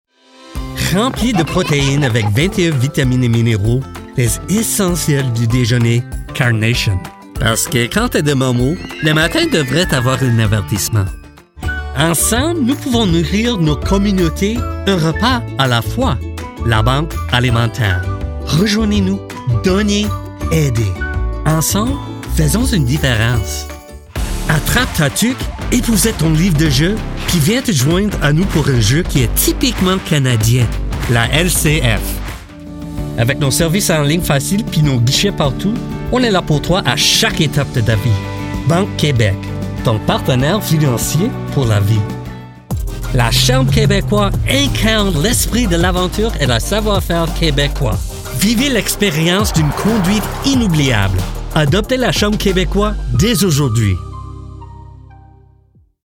Muestras de voz en idiomas extranjeros
Demo comercial
BarítonoAltoBajo